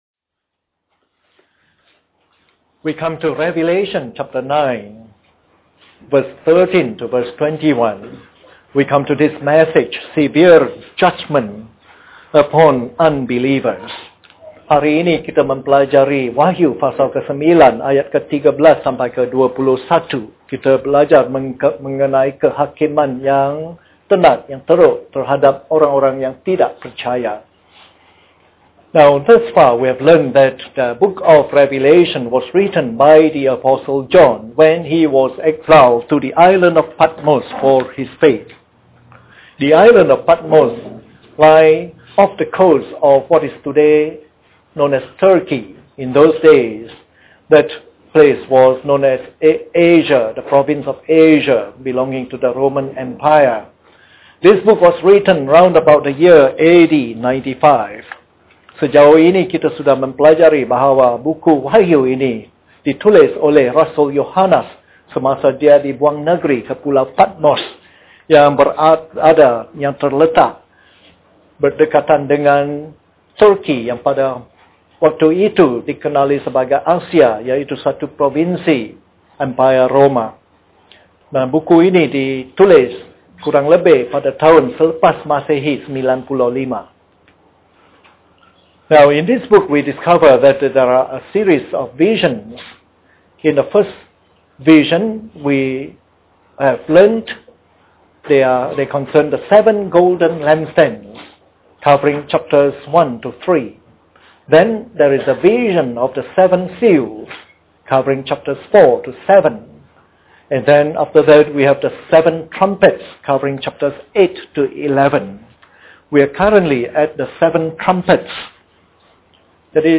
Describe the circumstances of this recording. This is part of the morning service series on “Revelation”.